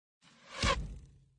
arrow.opus